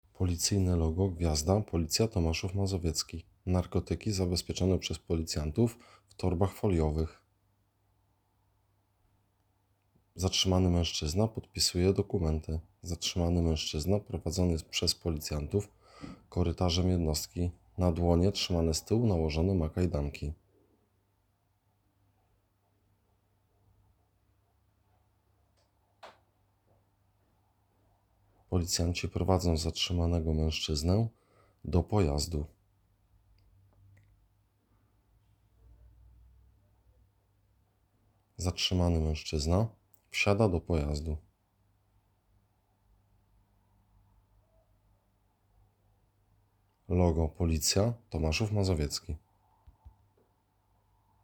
Nagranie audio Audiodeskrypcja_do_filmu.m4a